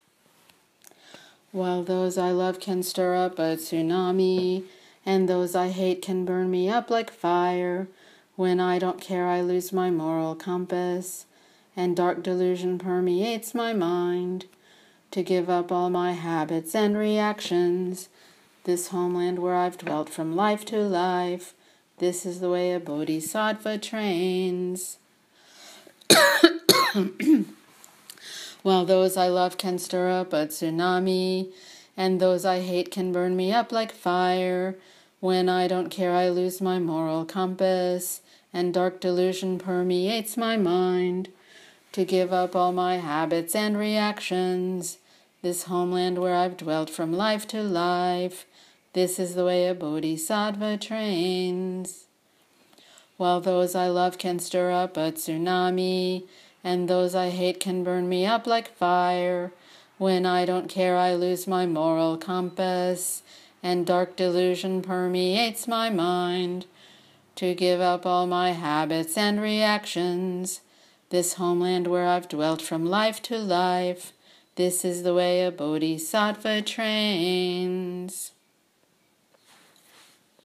Verse 2 chanted 3x.